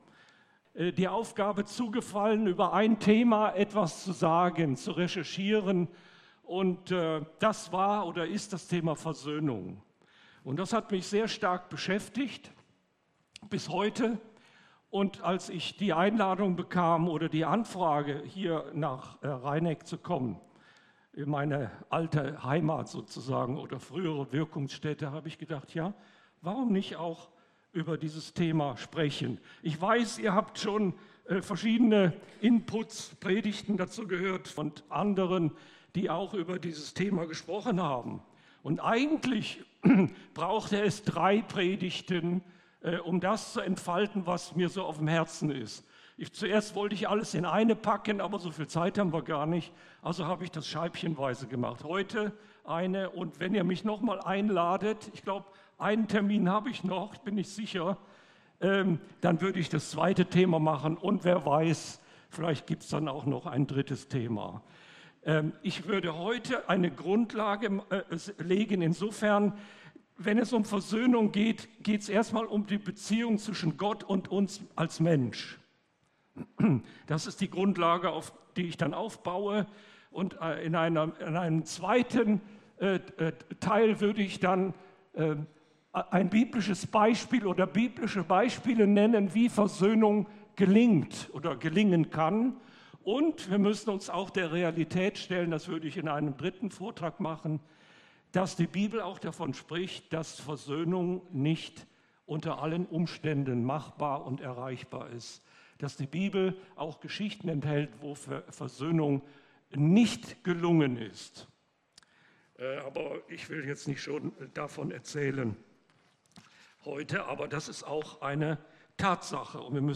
Predigt
Hier hörst du die Predigten aus unserer Gemeinde.